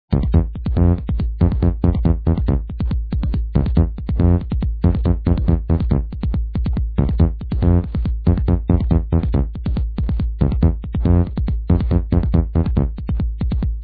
Trance/Progressive ID needed...